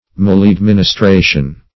Maleadministration \Male`ad*min`is*tra"tion\
(m[a^]l`[a^]d*m[i^]n`[i^]s*tr[=a]"sh[u^]n), n.